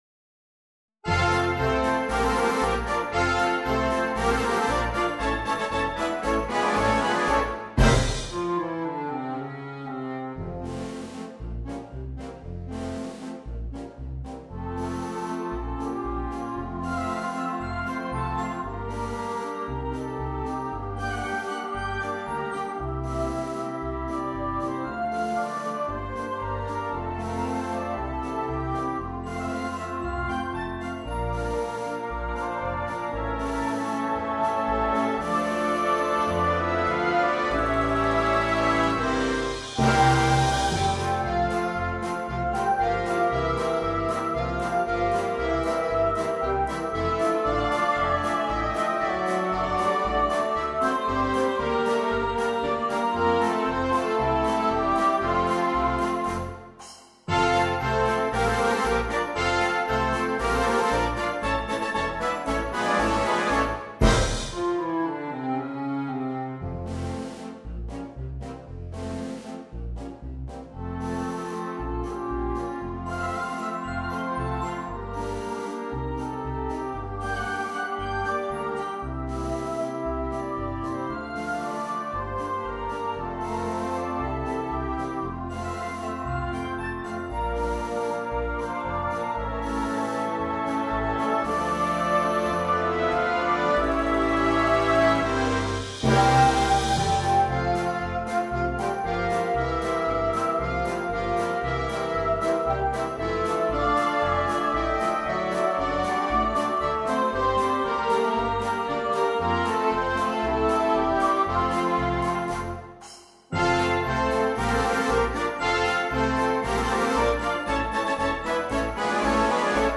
Per voce (ad lib.) e banda